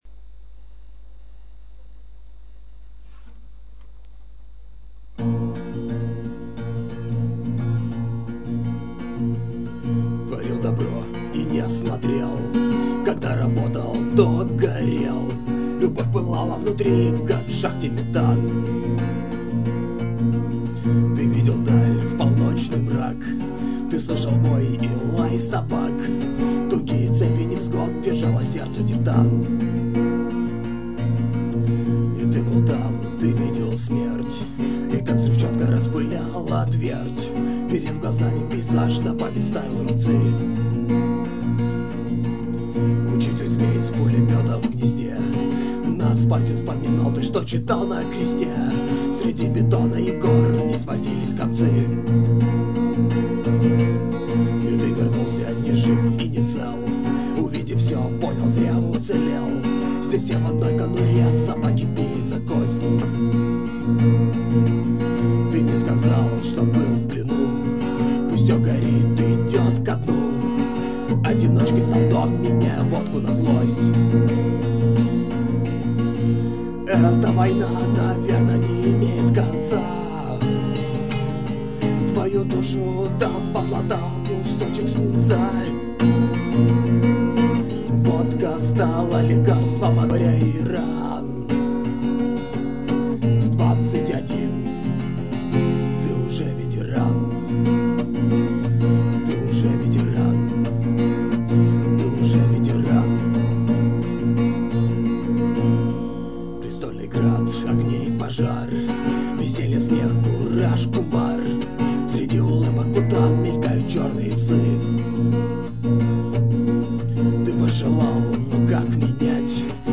A2: Бардрок